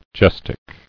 [ges·tic]